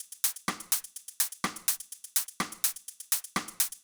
Drumloop 125bpm 02-B.wav